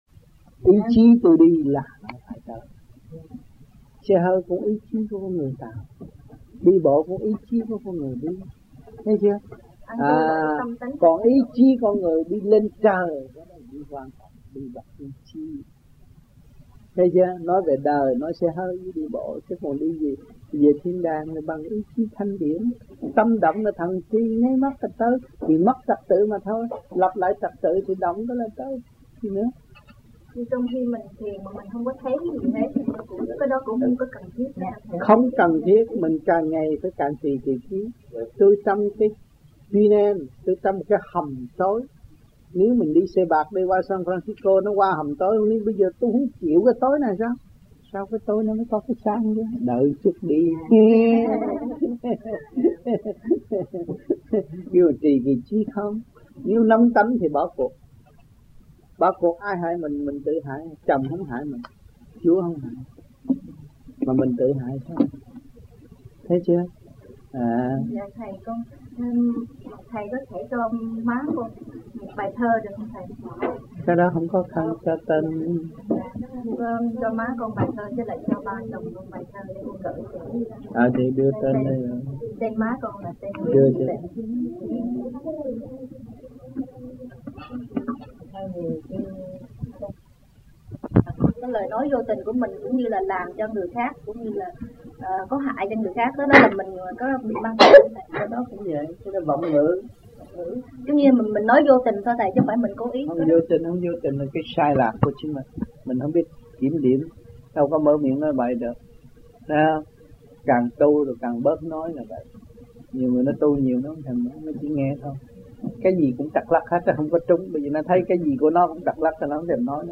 United States Trong dịp : Sinh hoạt thiền đường >> wide display >> Downloads